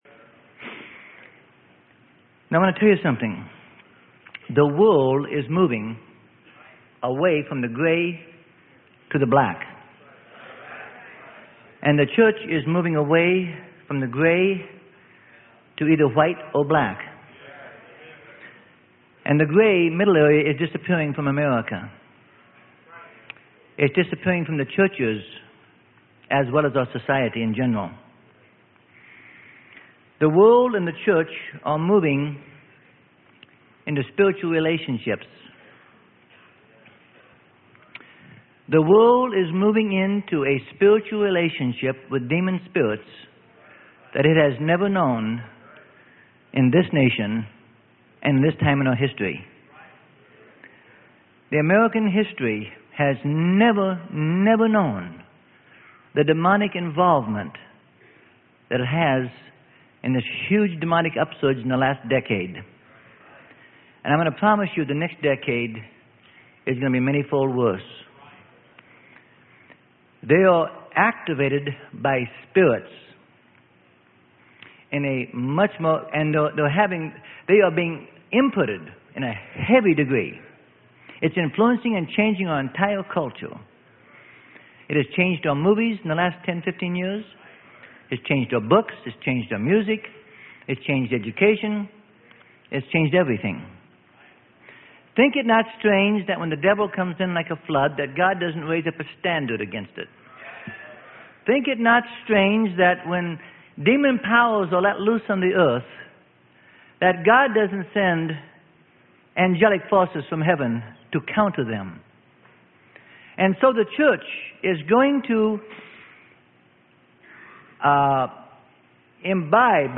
Sermon: 1984 STATE OF THE UNION ADDRESS - PART 3 - Freely Given Online Library